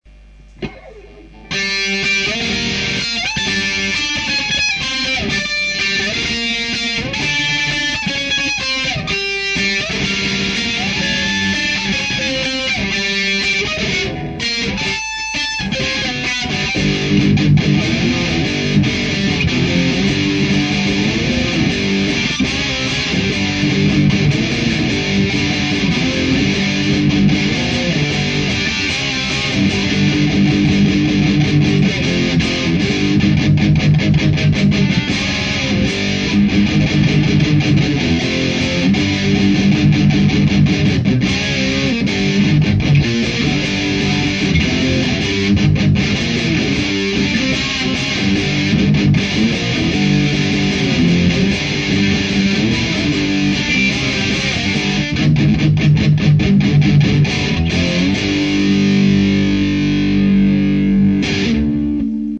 Bonjour voila j'ai fait une petite prise d'un riff que j'ai inventé (plutot metal) et j'aimerai avoir votre avis sur la qualité du riff et la qualité de la distortion. pour info j'ai une lag rockline metalmaster (lh) et un marshall 8080. j'ai mi le micro pc devan l'ampli, et enregistré avec soundforge, sans autres traitements.
le riff est cool mais faudrait que tu bosse un peu la clareté de ton jeu rythmique, notamment la precision du mediator quand tu joue un power chord. et sinon niveau son ca va, meme si c'est pas ideal pour jouer en palm muting (moi jai pas un meilleur son, je joue sur lordi avec gratte et multieffet pourri)
riffmetal1.mp3